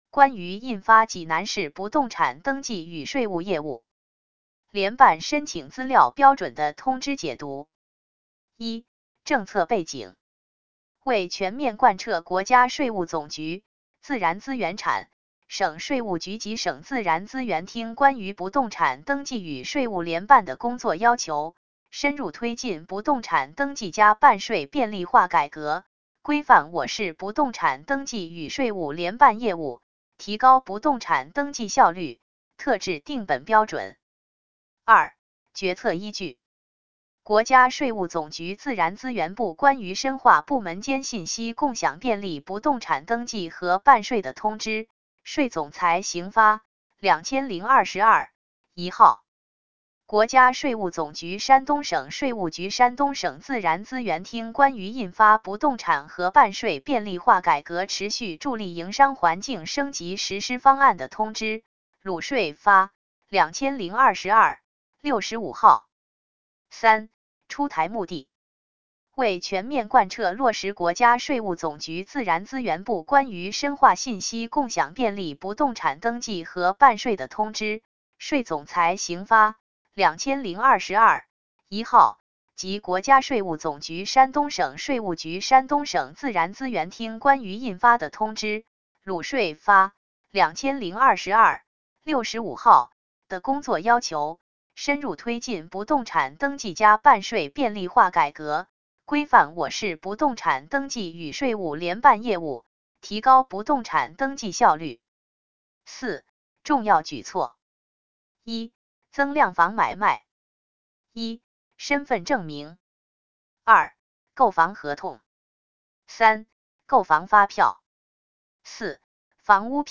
济南市自然资源和规划局 有声朗读 【有声朗读】济南市自然资源和规划局 国家税务总局济南市税务局关于印发济南市不动产登记与税务业务联办申请资料标准的通知